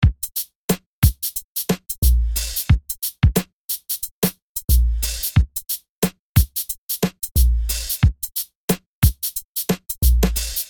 Music loop drums 005